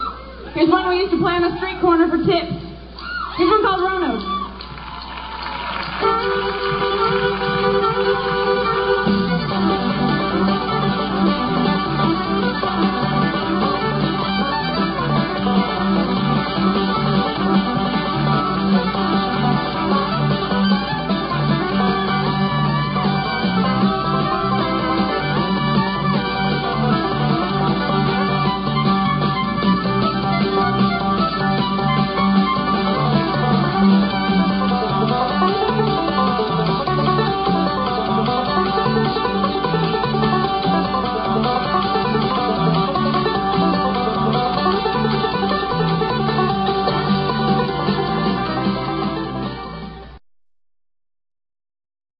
CONCERT CLIPS